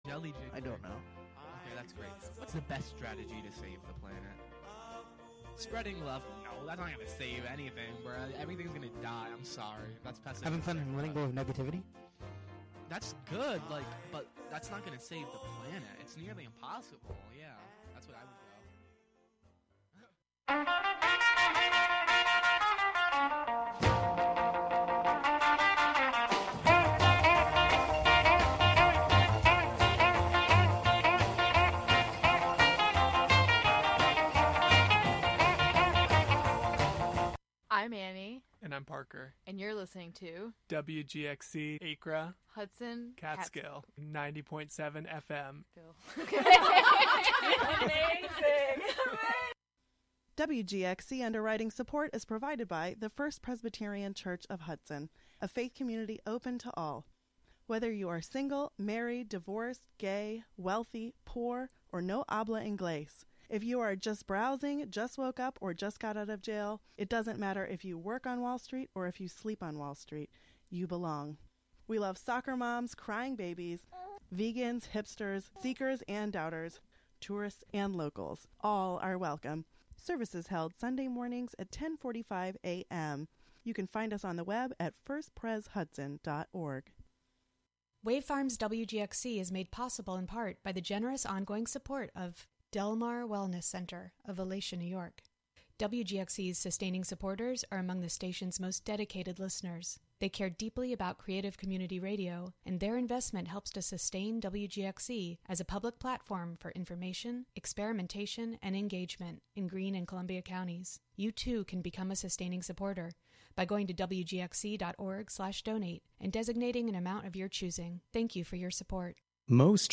Featuring gospel, inspirational, soul, R&B, country, christian jazz, hip hop, rap, and praise and worship music of our time and yesteryear; interwoven with talk, interviews and spiritual social commentary